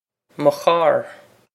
mo charr muh khawr
This is an approximate phonetic pronunciation of the phrase.